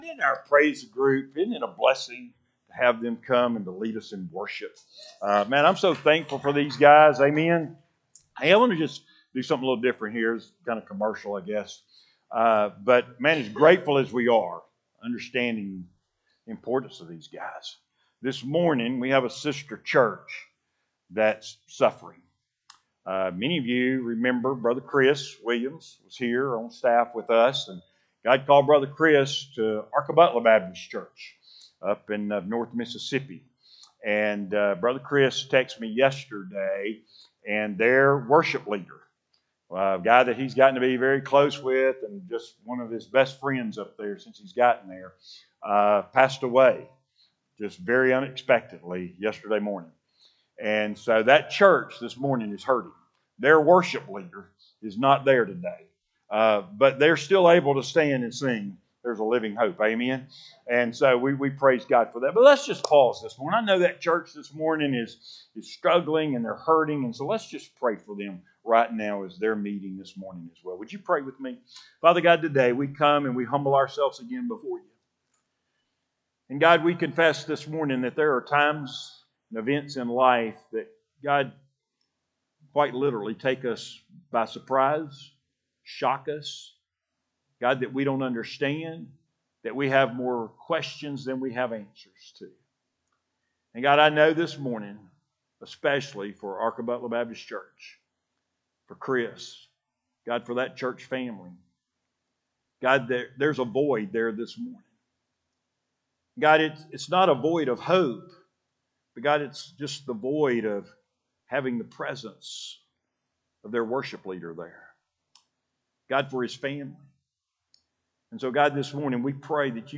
Audio Sermons: Videos of service can be seen on Facebook page - Trinity Baptist Church